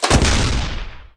Npc Robot Missilefire Sound Effect
npc-robot-missilefire-1.mp3